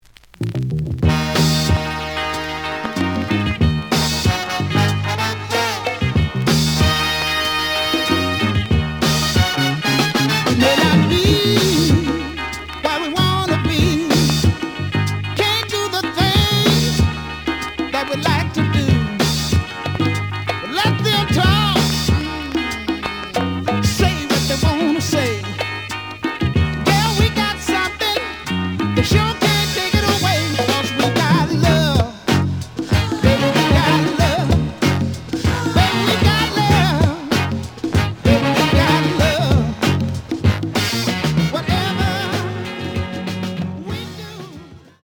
The audio sample is recorded from the actual item.
●Format: 7 inch
●Genre: Funk, 70's Funk
Some click noise on later half on B side due to scratches.